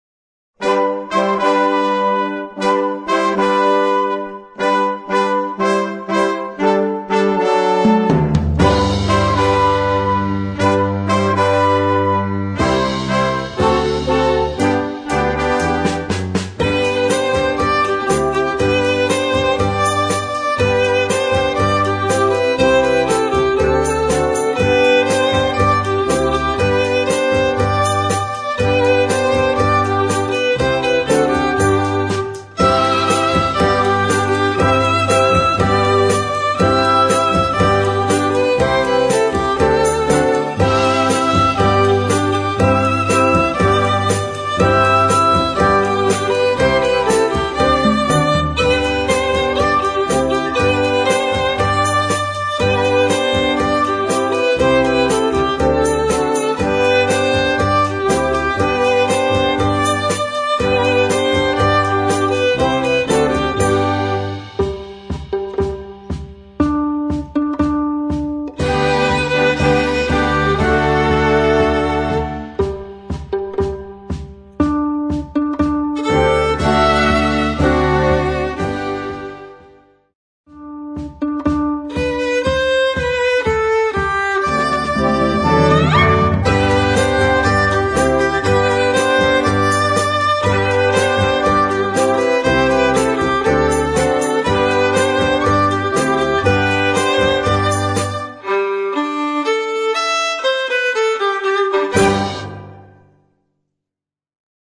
Partitions pour ensemble flexible, 4-voix + percussion.